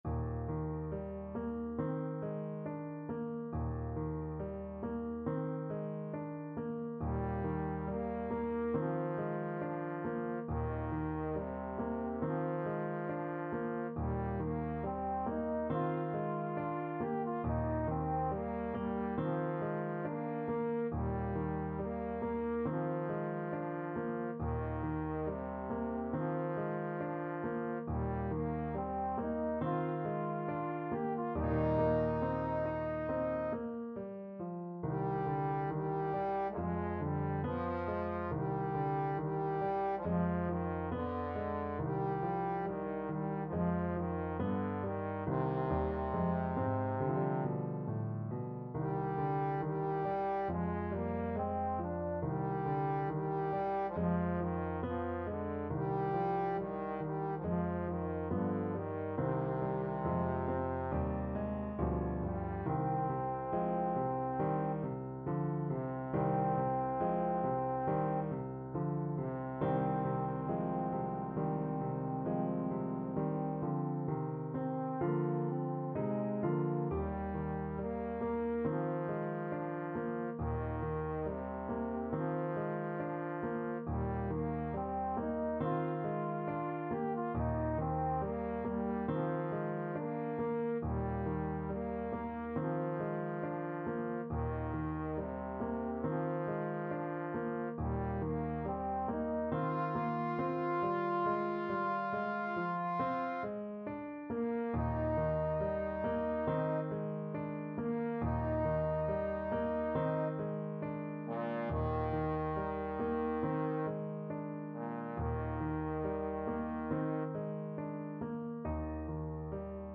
Trombone
4/4 (View more 4/4 Music)
~ =69 Poco andante
Eb major (Sounding Pitch) (View more Eb major Music for Trombone )
Classical (View more Classical Trombone Music)
ilyinsky_op13_7_berceuse_TBNE.mp3